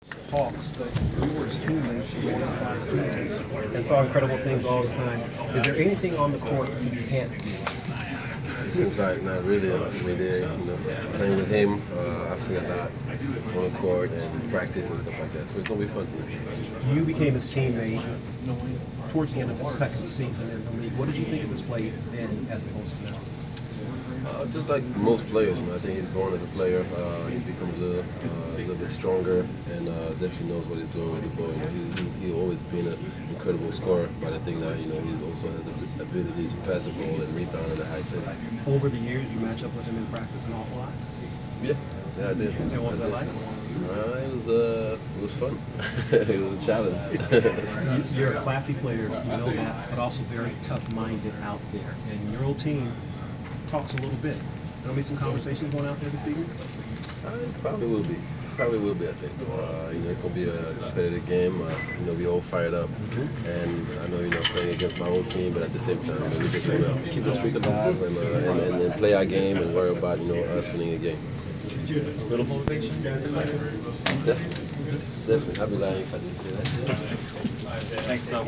Inside the Inquirer: Pregame presser with Atlanta Hawk Thabo Sefolosha (1/25/15)
We attended the pregame presser of Atlanta Hawks’ forward Thabo Sefolosha before his team’s home contest against the Oklahoma Thunder on Jan. 25. Topics included facing his former team and defending Kevin Durant in practice.